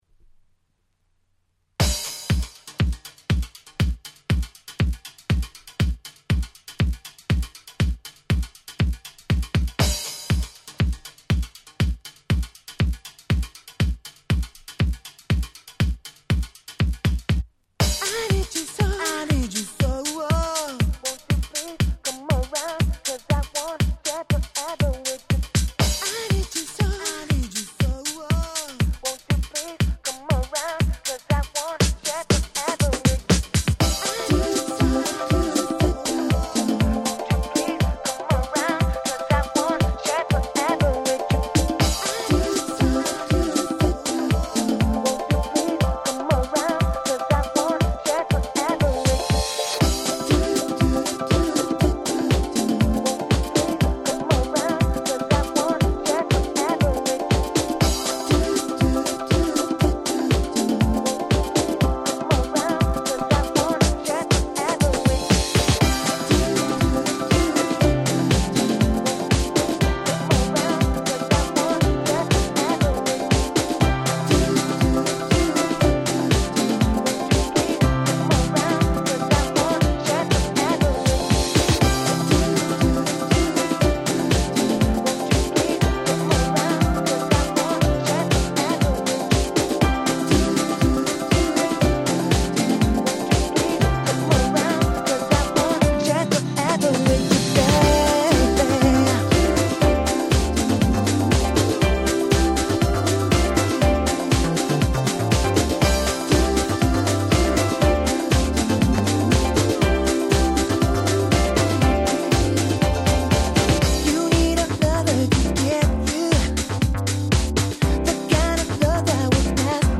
07' Nice Japanese House !!